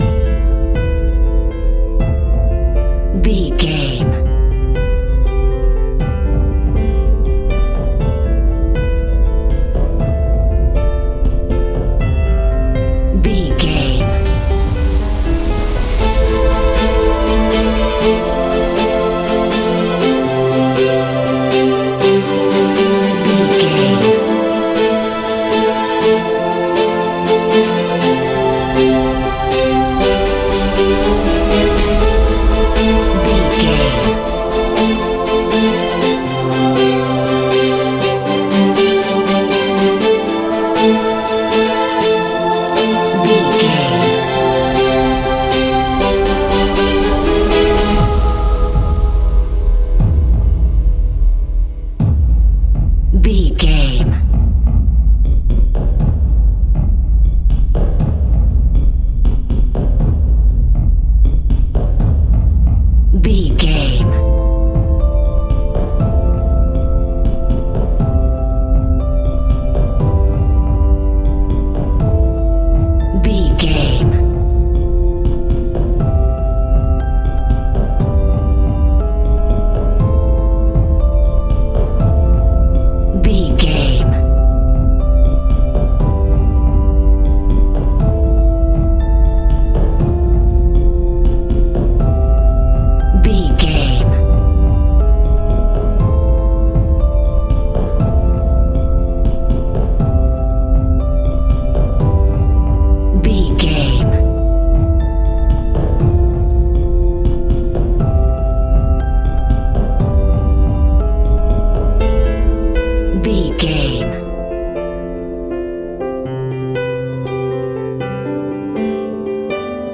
Aeolian/Minor
dramatic
strings
percussion
synthesiser
brass